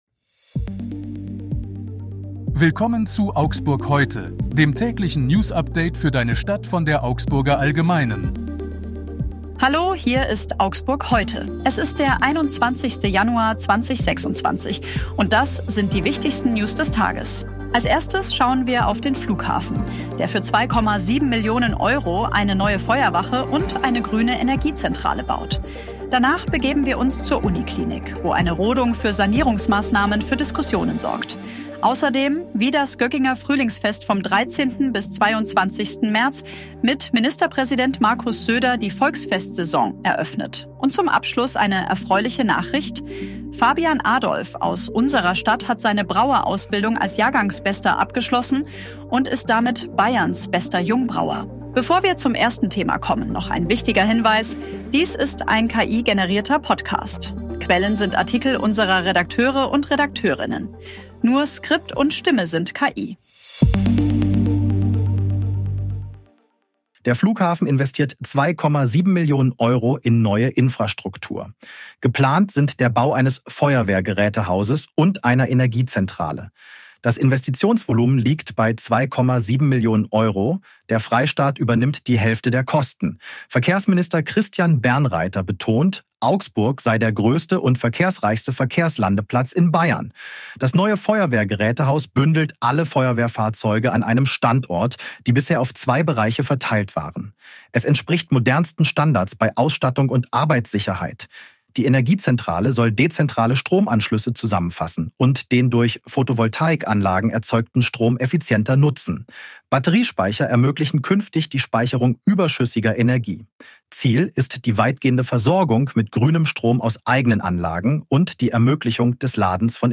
Nur Skript und Stimme sind KI.